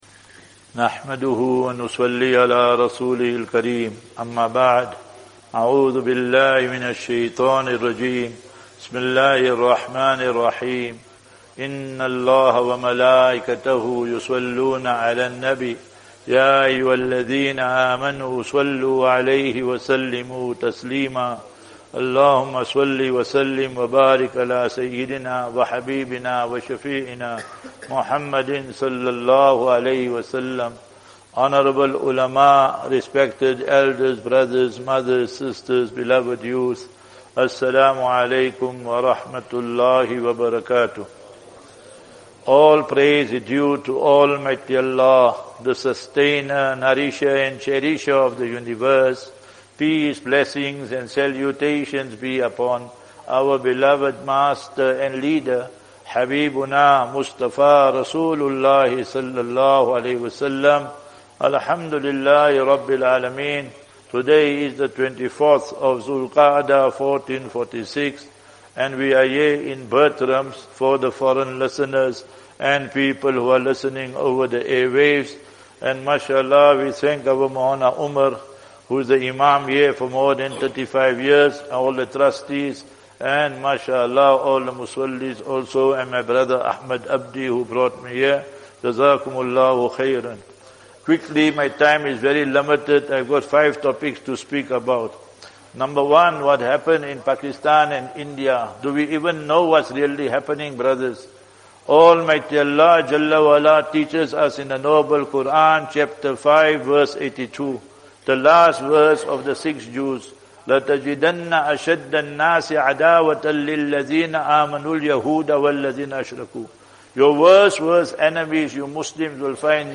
23 May 23 May 2025 - Jumu'ah Lecture in Masjid-E-Muqeemus Salaat - Betrams